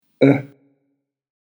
[ö]